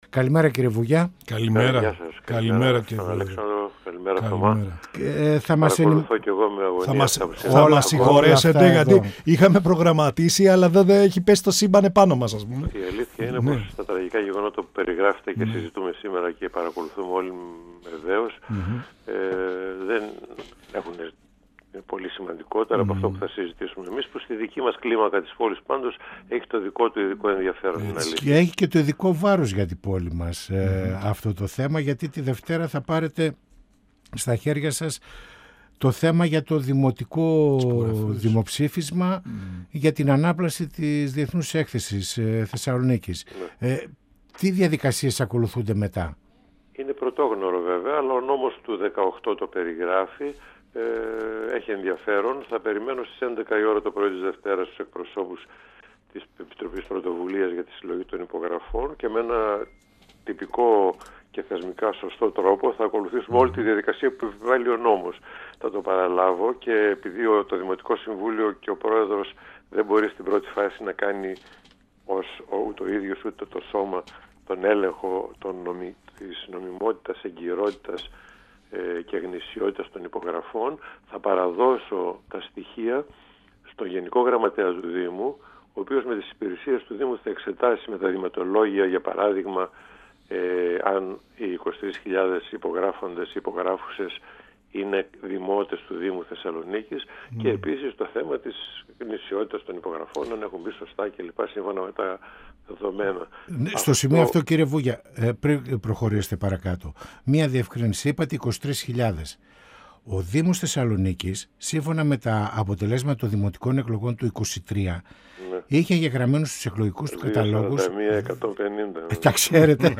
Στην αναμενόμενη παράδοση από την Οργανωτική Επιτροπή Πολιτών της Θεσσαλονίκης των 23.314 υπογραφών στον Πρόεδρο του Δημοτικού Συμβουλίου, για τη διενέργεια Τοπικού Δημοψηφίσματος, με το αίτημα μεταφοράς της ΔΕΘ εκτός πόλης και της δημιουργίας μητροπολιτικού πάρκου στην υπάρχουσα θέση της Έκθεσης αναφέρθηκε ο Πρόεδρος του Δημοτικού Συμβουλίου του Δήμου Θεσσαλονίκης Σπύρος Βούγιας , μιλώντας στην εκπομπή «Πανόραμα Επικαιρότητας» του 102FM της ΕΡΤ3.
Συνεντεύξεις